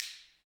Index of /90_sSampleCDs/Roland L-CD701/PRC_Clap & Snap/PRC_Snaps